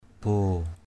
/bo:/ (d.) cái vồ, chà vồ = massue en bois.